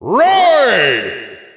Announcer pronouncing Roy Koopa in German.
Roy_Koopa_German_Announcer_SSBU.wav